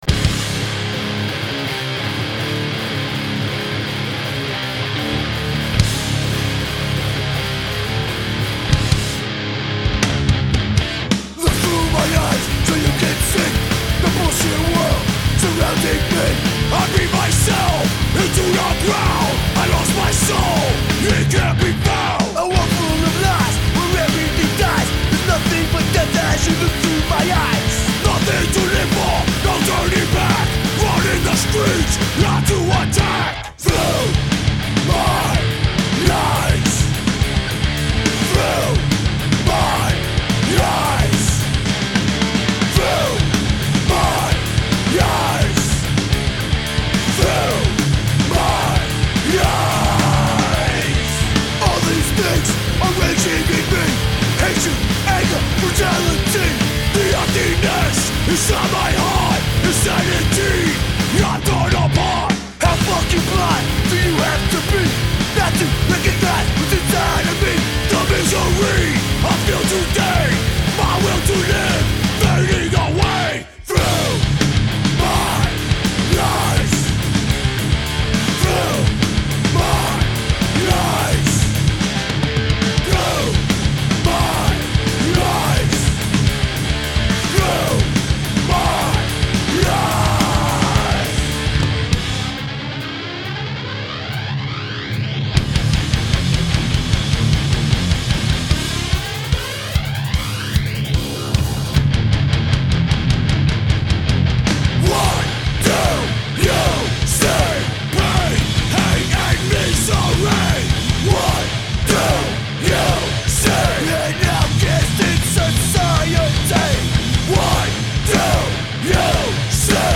super scheibe.. madball mit mehr rap!